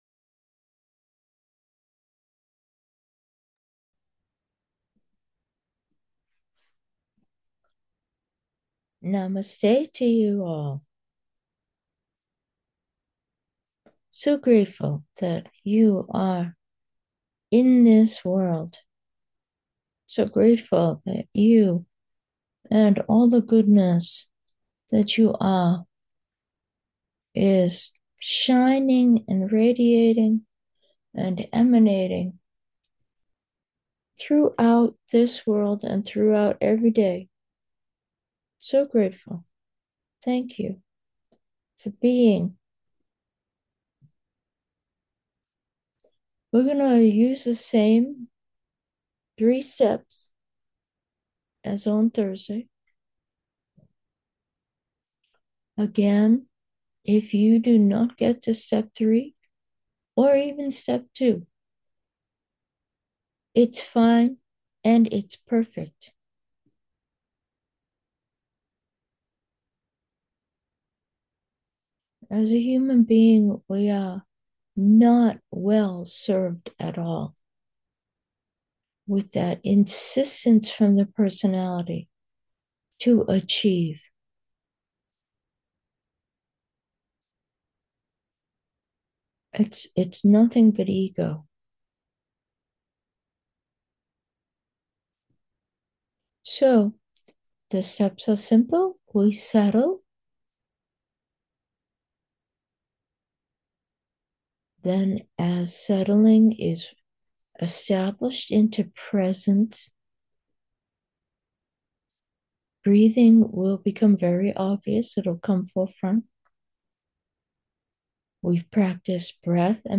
Gentle meditation.